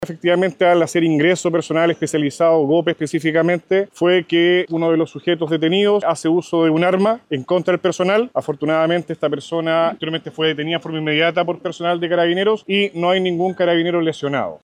El coronel Jorge Guaita, prefecto de Carabineros Marga Marga, informó que, pese a lo ocurrido, ningún carabinero resultó lesionado.